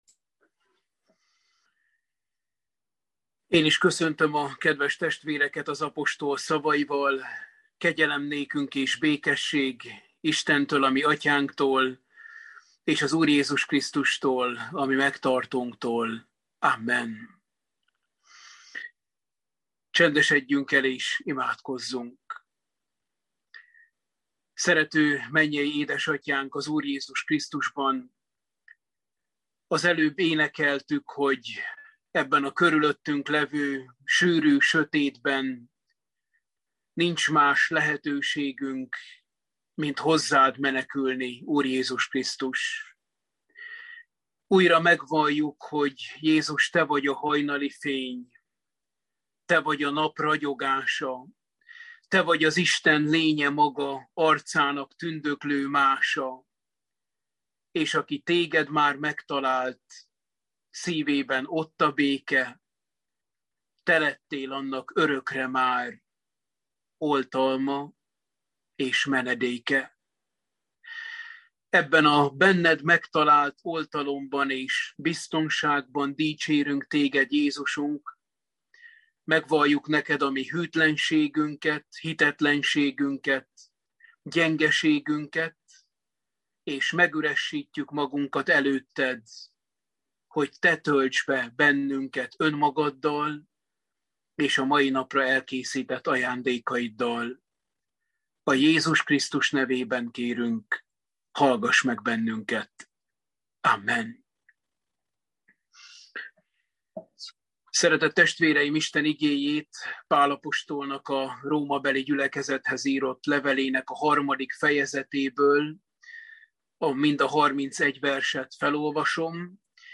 Római levél – Bibliaóra 07